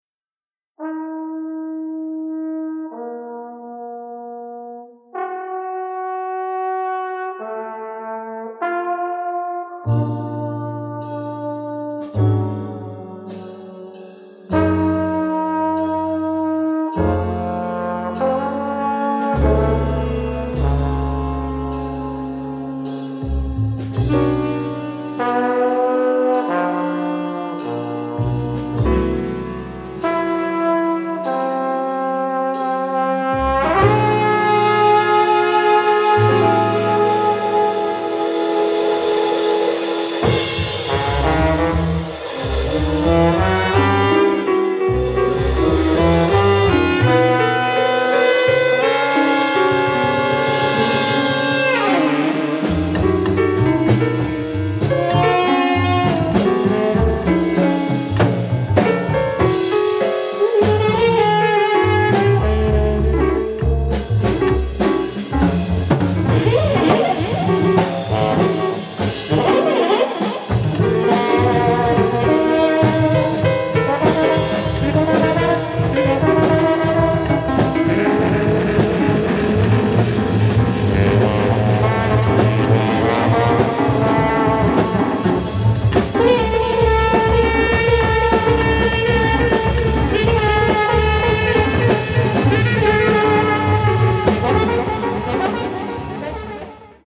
Italian Free Jazz milestone.